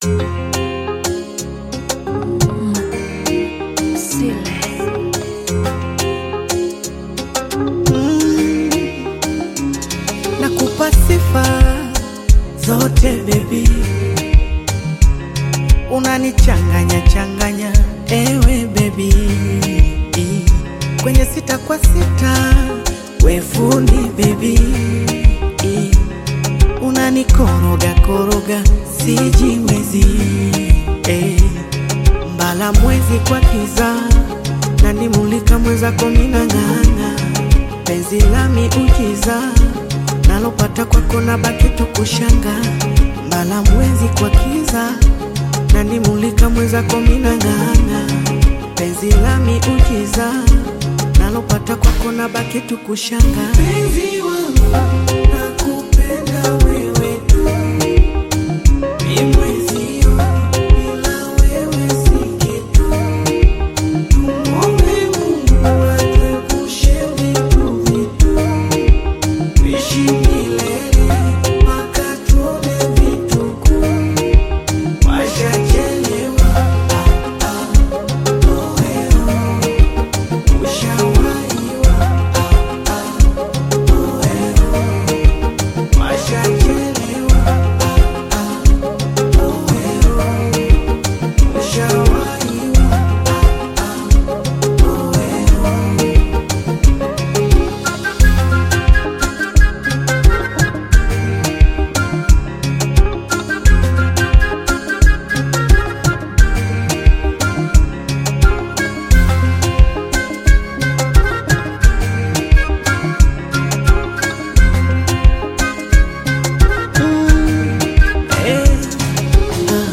Tanzanian Bongo Flava
Bongo Flava song